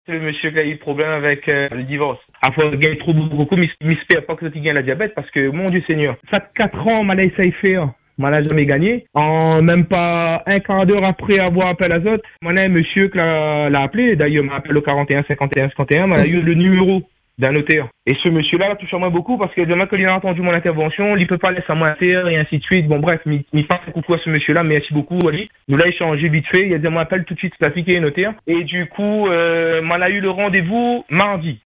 À bout, il décide de faire ce qu’il n’avait jamais tenté : en parler à l’antenne de Free Dom.